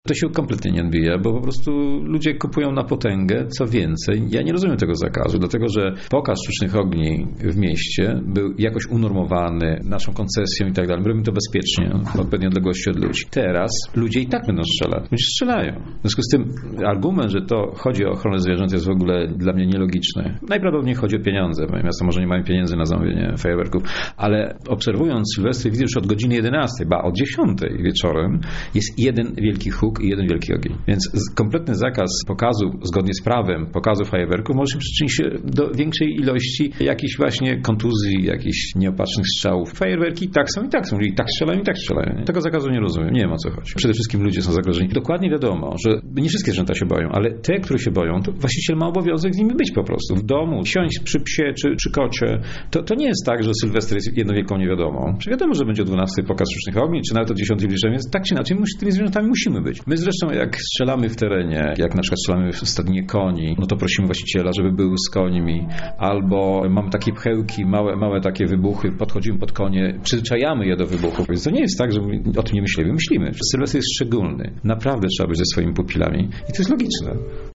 Przedstawiciele branży związanej ze sztucznymi ogniami sprzeciwiają się takiej idei. Twierdzą, że zakaz minąłby się z celem.